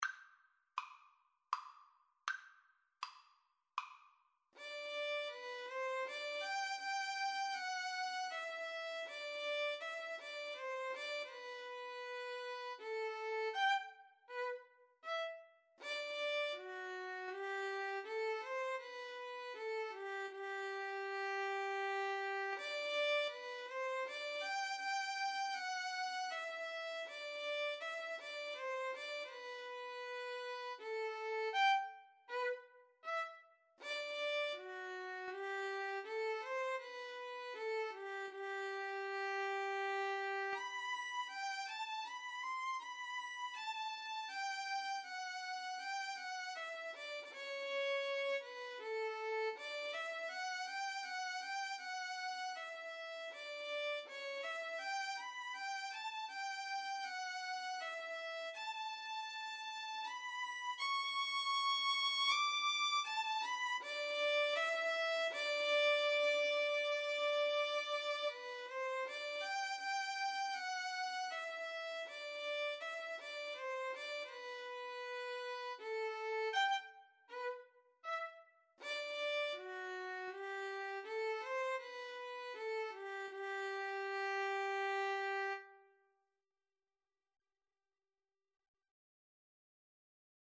Play (or use space bar on your keyboard) Pause Music Playalong - Player 1 Accompaniment transpose reset tempo print settings full screen
Andante
G major (Sounding Pitch) (View more G major Music for Violin-Viola Duet )
Classical (View more Classical Violin-Viola Duet Music)